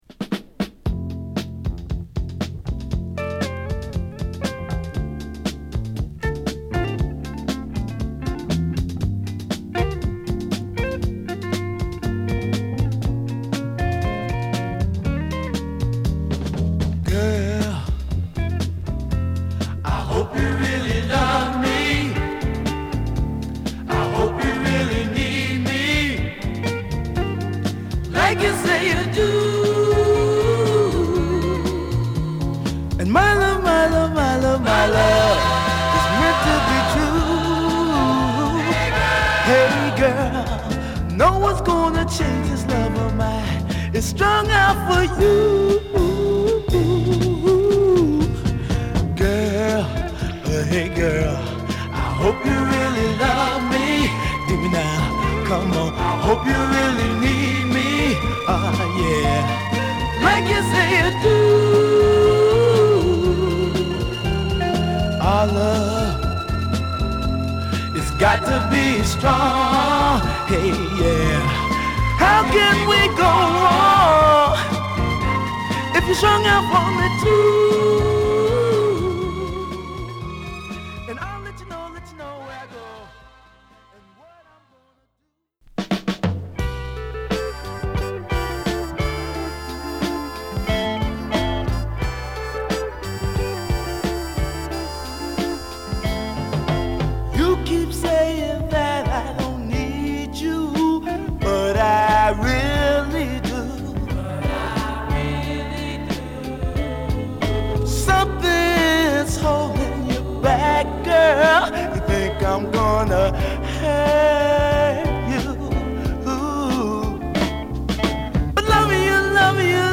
両面共に程良いマイナー感を持ったグッド・メロウ・チューンです！